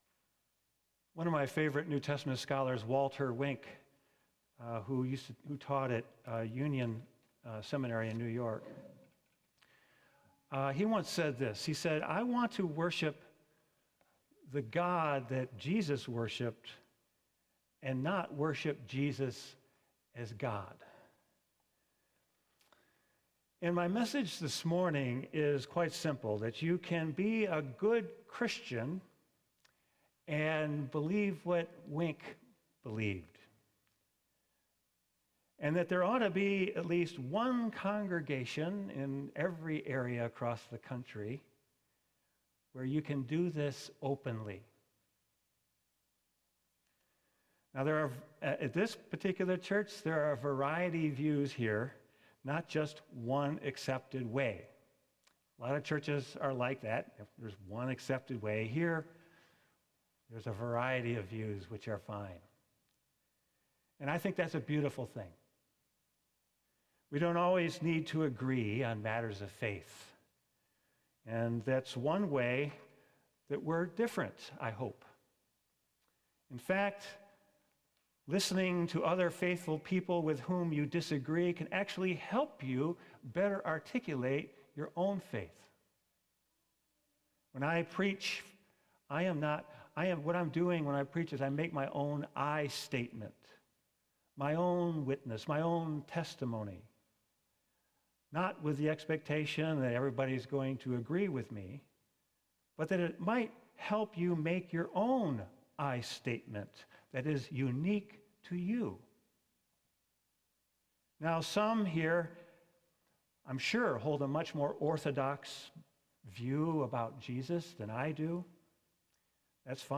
sermon-5-1-22.mp3